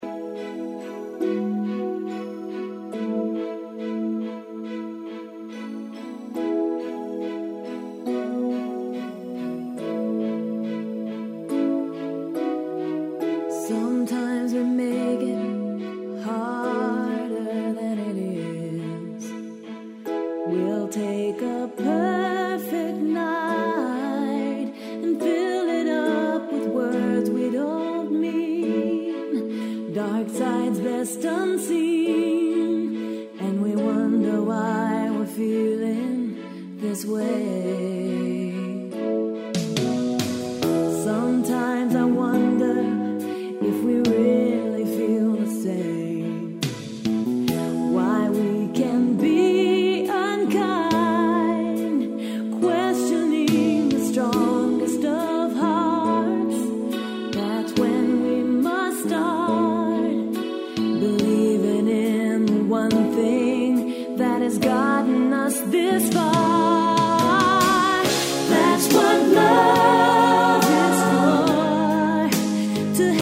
Demo Vocal